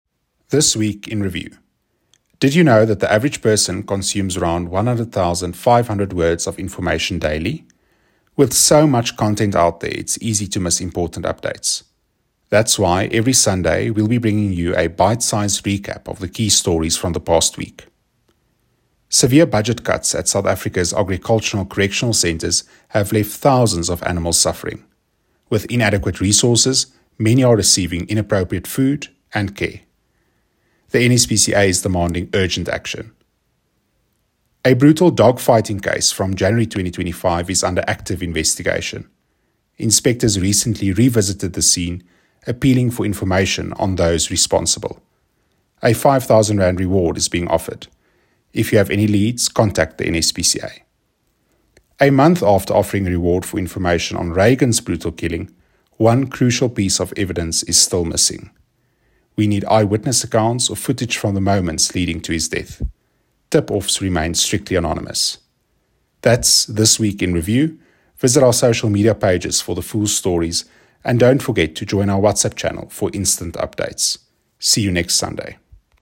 ED.-1-Voiceover.mp3